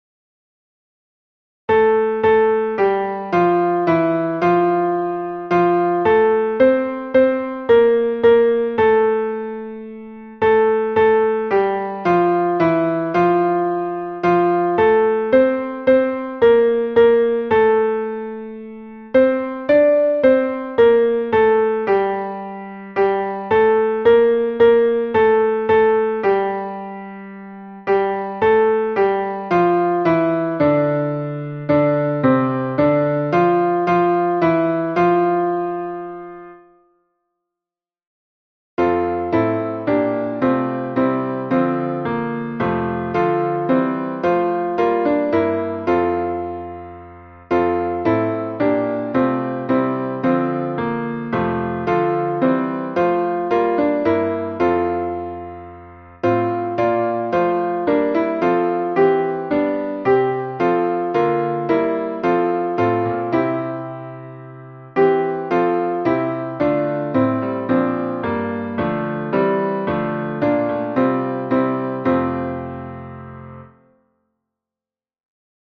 MP3 version piano
Chant d effleure alto (piano)
chant-d-effleure-alto.mp3